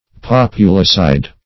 Search Result for " populicide" : The Collaborative International Dictionary of English v.0.48: Populicide \Pop"u*li*cide`\, n. [L. populus people + caedere to kill.] Slaughter of the people.
populicide.mp3